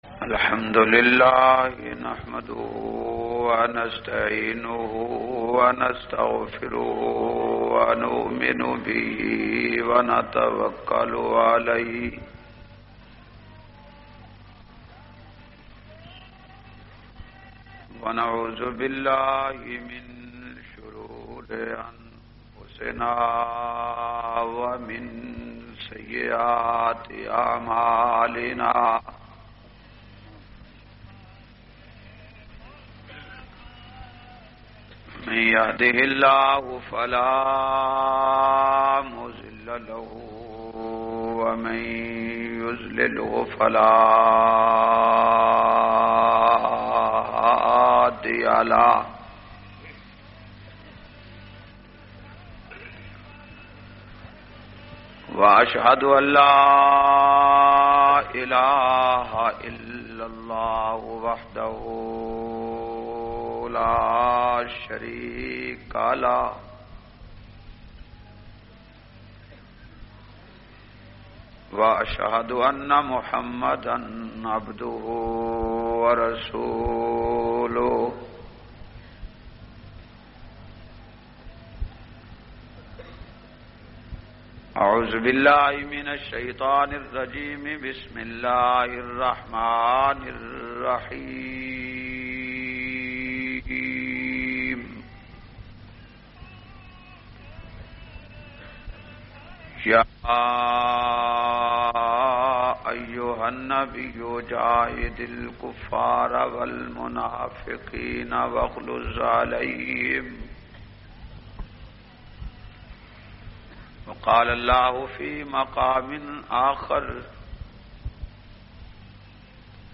353- Azmat e Sahaba BahawalPur me Pehli Taqreer.mp3